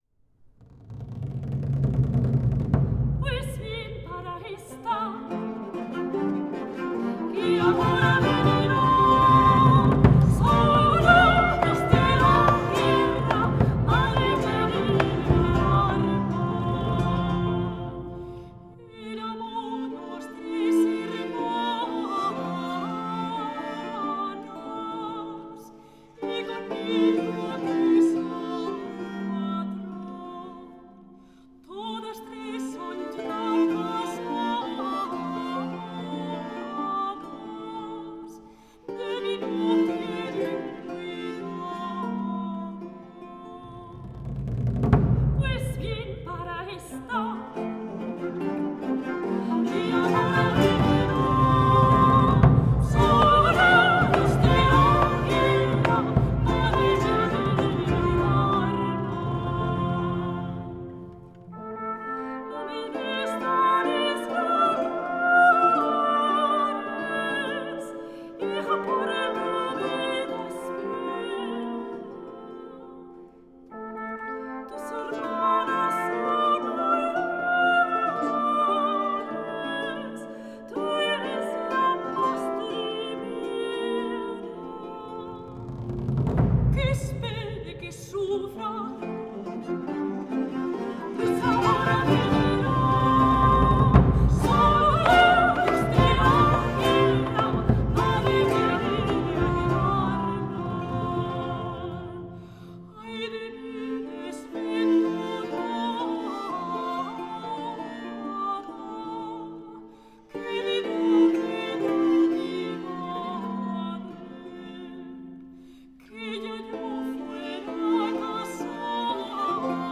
Canto
Música tradicional